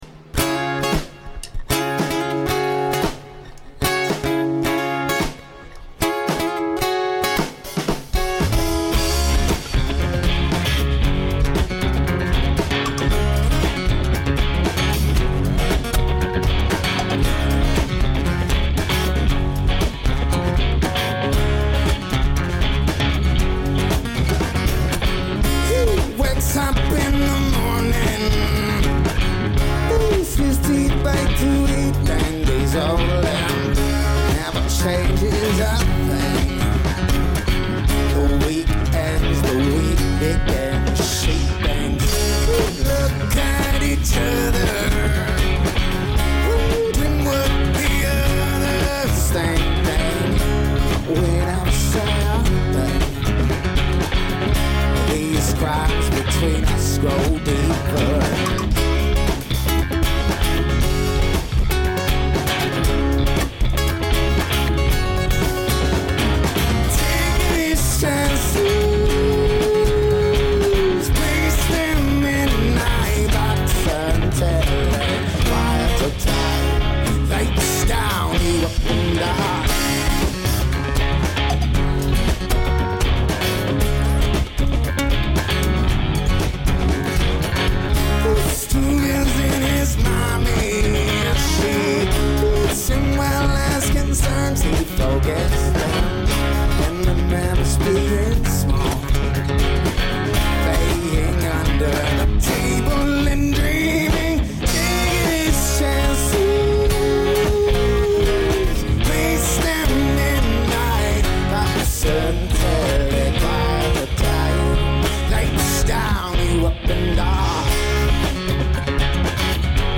cover live